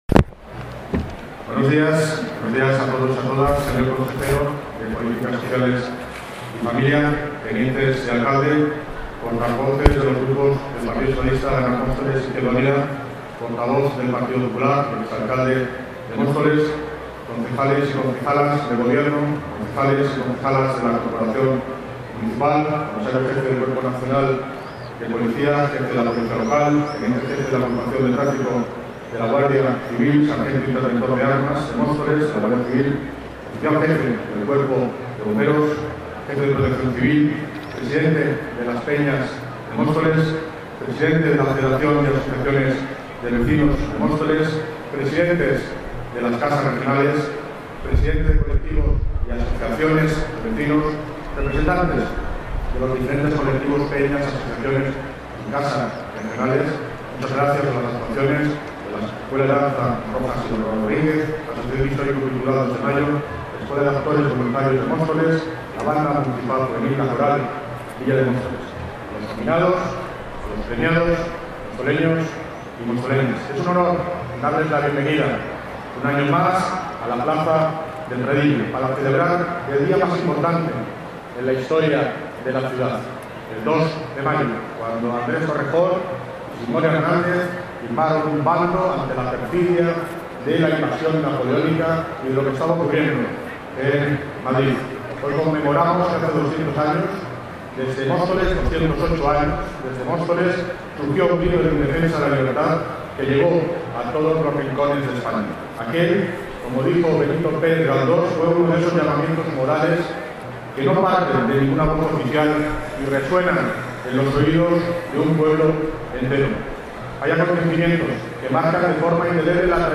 David Lucas preside el acto de Homenaje a los Héroes de la Independencia
Sonido - David Lucas (Alcalde de Móstoles) Acto Institucional
David Lucas. Acto Institucional.mp3